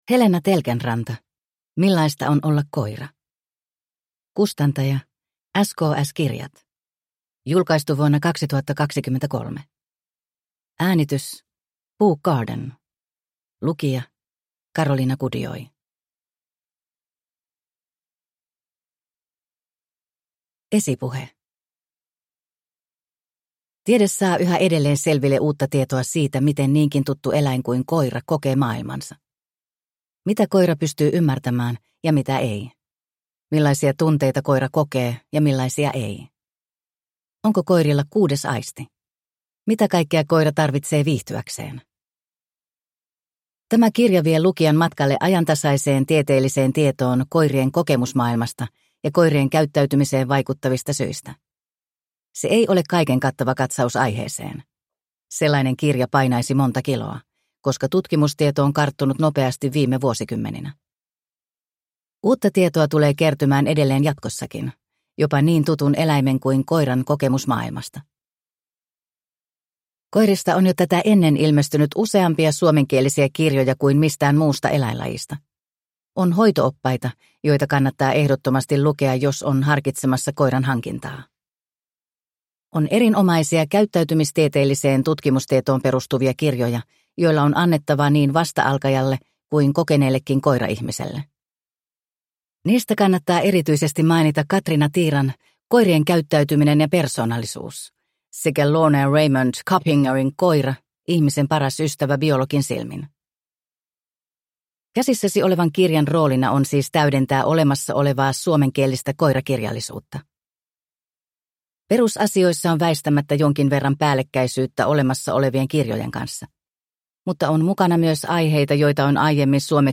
Millaista on olla koira? – Ljudbok